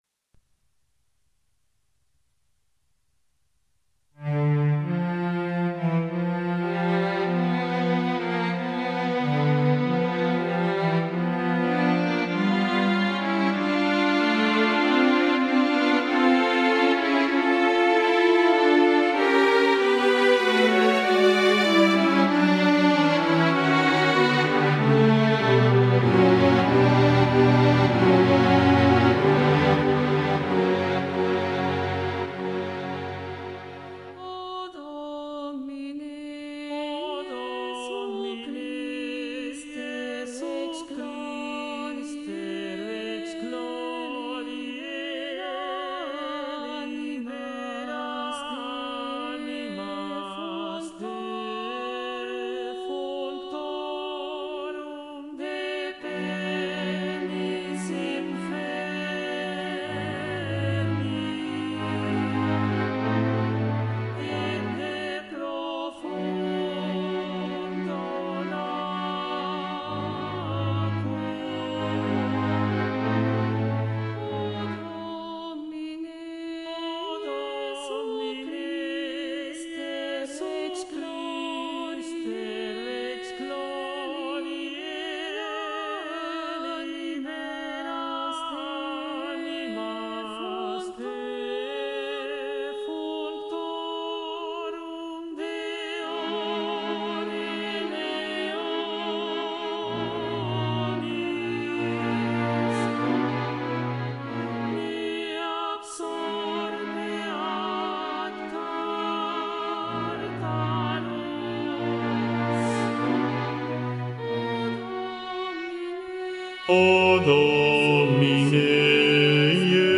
Bajo
Mp3 Profesor
2.-Ofertorio-BAJO-VOZ.mp3